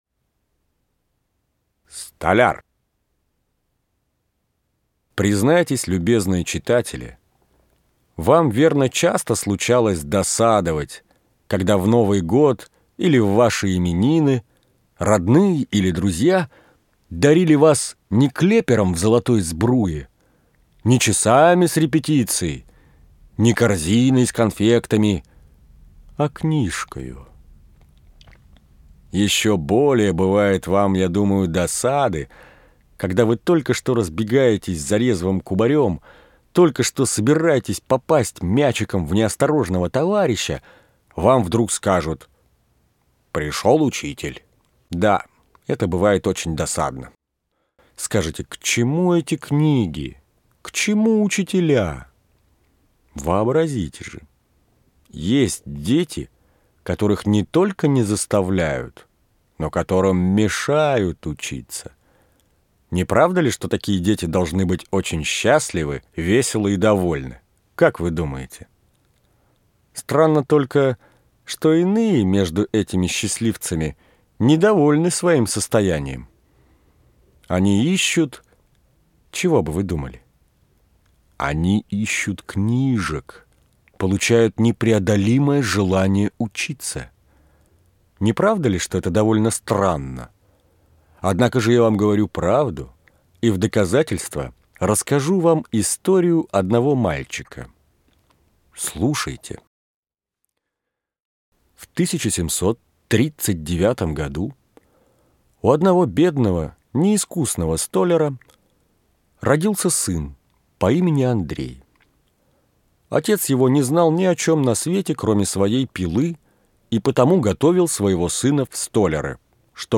Столяр - аудио рассказ Одоевского В.Ф. Рассказ о том, как из сына неграмотного столяра вырос знаменитый французский архитектор Андрей Рубо.